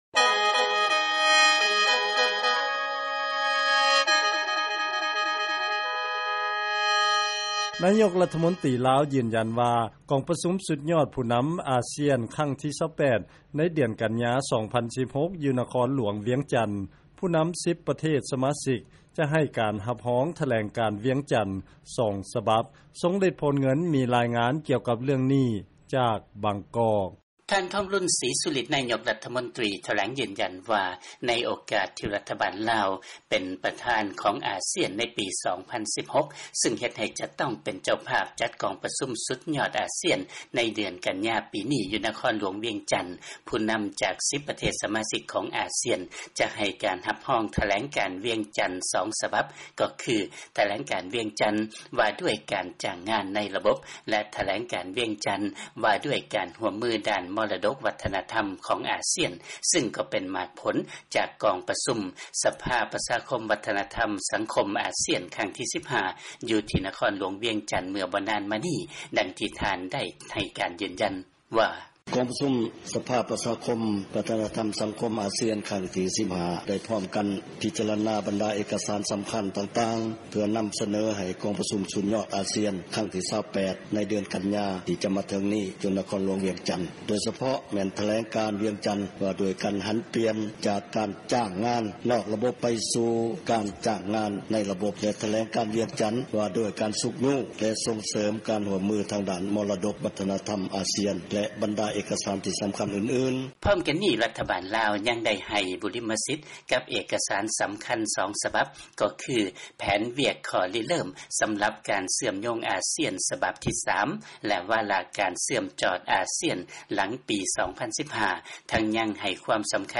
ເຊີນຟັງ ລາຍງານ ຜູ້ນຳ 10 ປະເທດສະມາຊິກ ອາຊຽນ ຈະຮັບຮອງ ຖະແຫລງການວຽງຈັນ 2 ສະບັບ